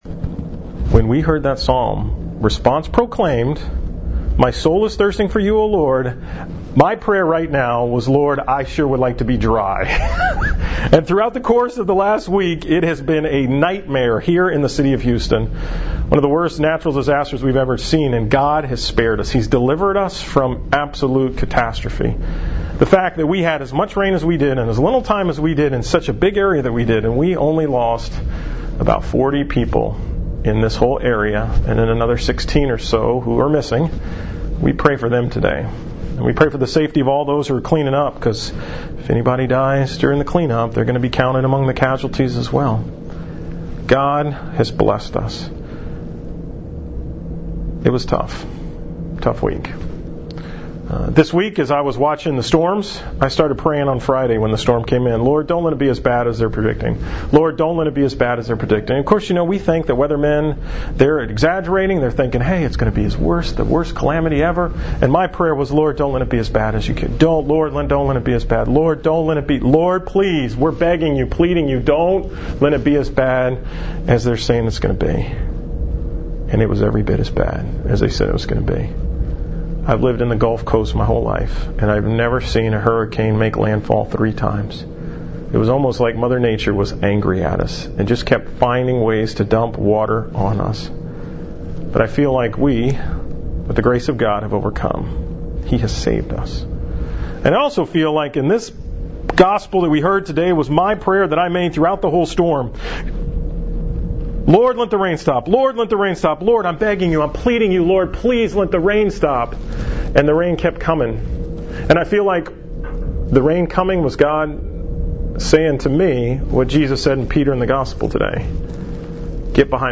From Mass on September 3, 2017.